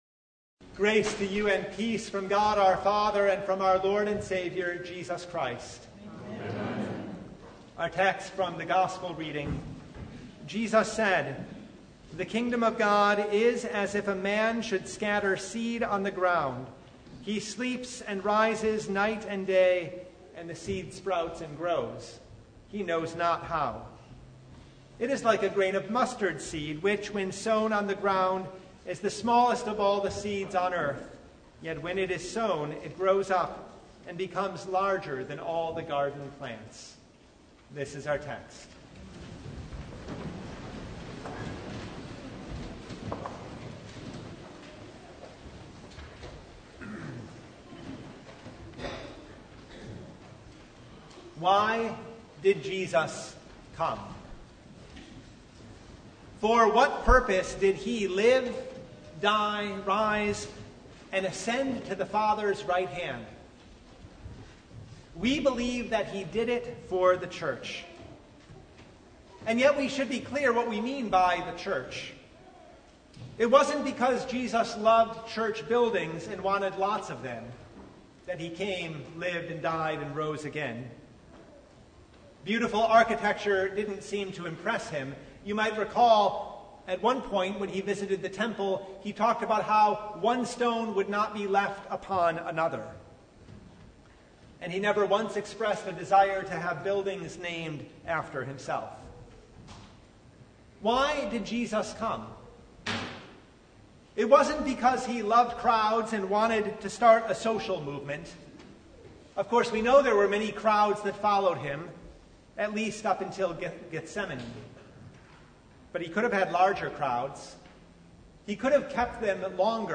Mark 4:26-34 Service Type: Sunday Why did Jesus come?